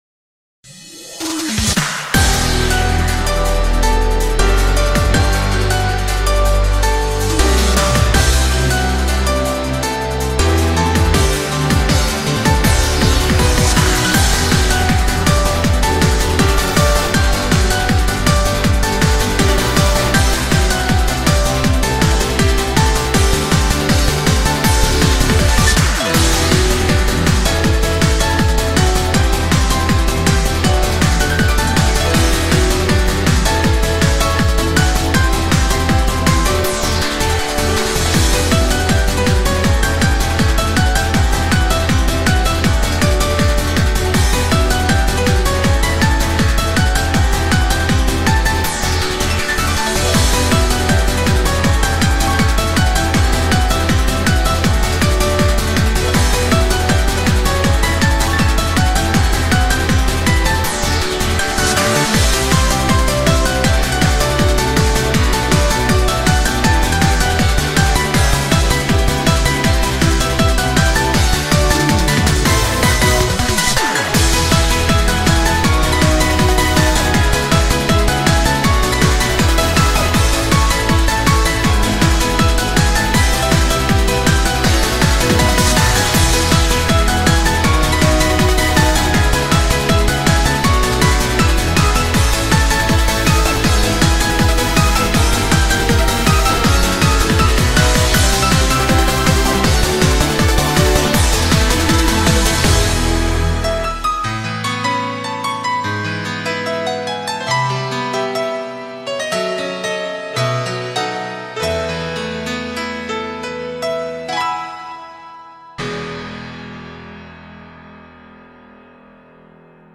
BPM60-160
Audio QualityPerfect (Low Quality)